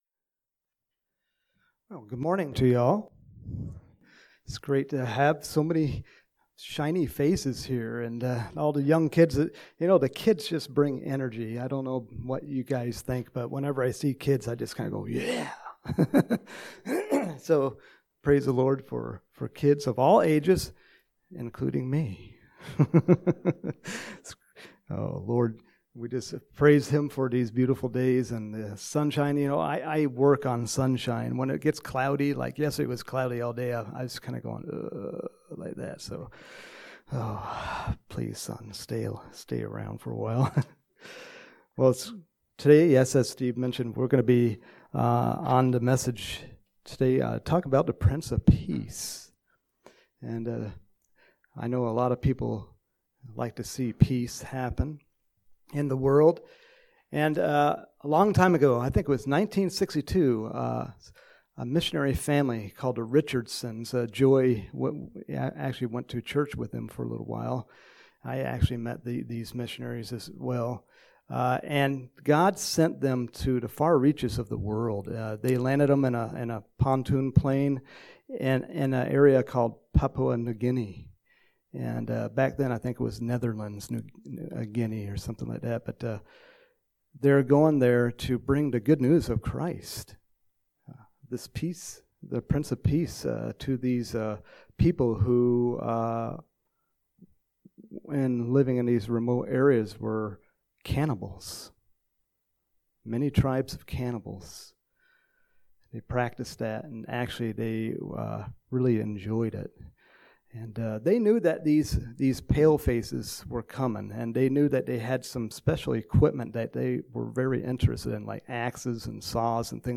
Home › Sermons › January 24, 2021